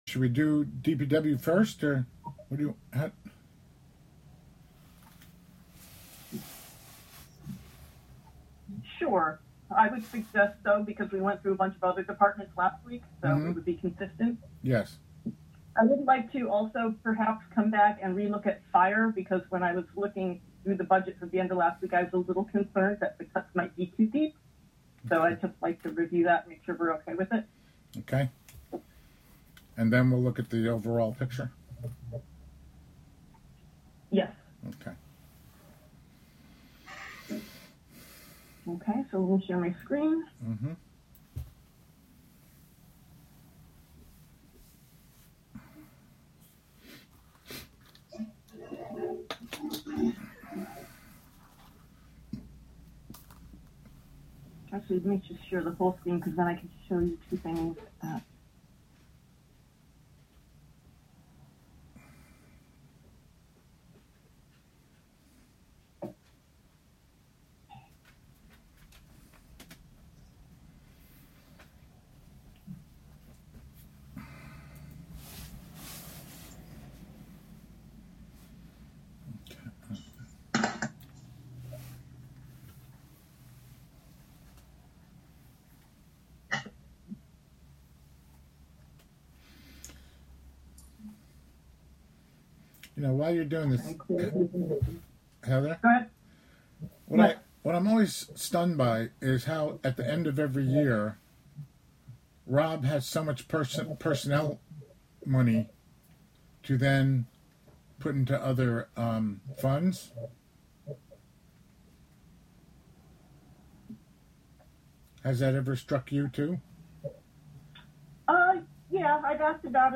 Live from the City of Hudson: Hudson BEA (Audio)